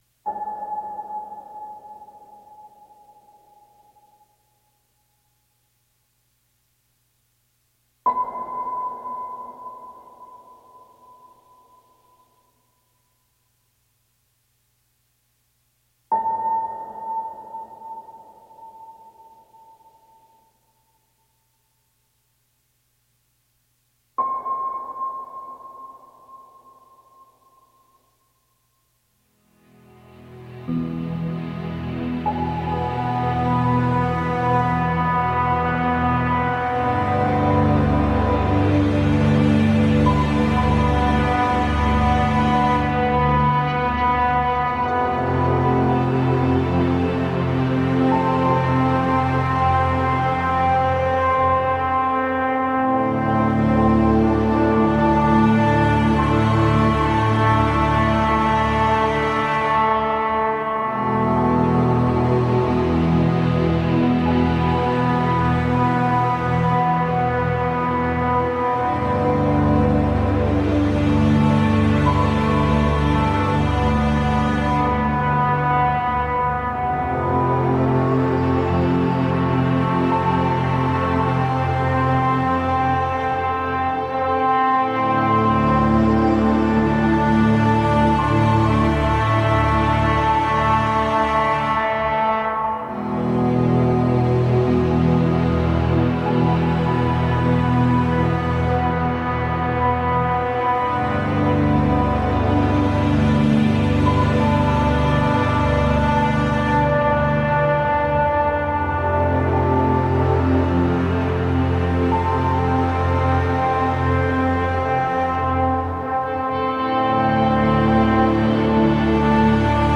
Ambient, orchestral drone music.
Tagged as: Ambient, Electronica, Drone